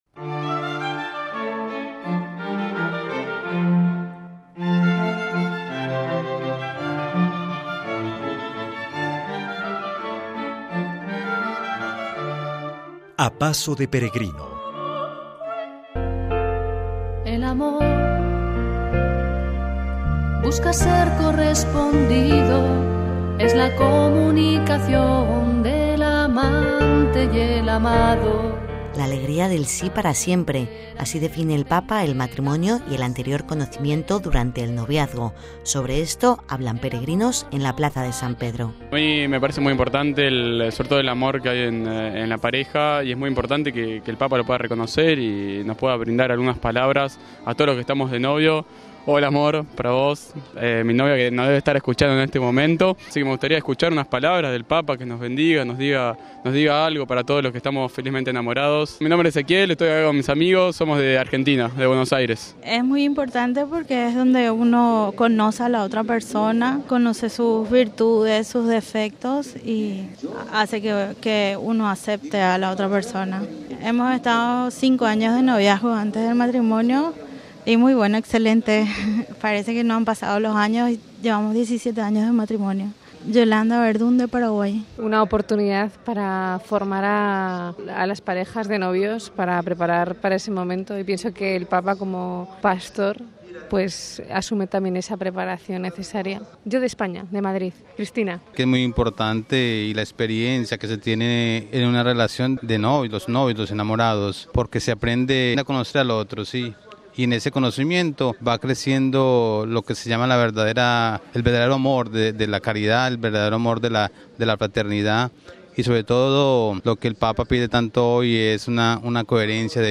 Miles de parejas llegaron al Vaticano para recibir la bendición del Obispo de Roma y para gritar su amor a los cuatro vientos. Sobre la importancia del noviazgo, la comprensión, el amor, el conocer al otro antes del matrimonio, el ser conscientes de “La Alegría del sí para siempre”, hablan peregrinos enamorados en la plaza de San Pedro.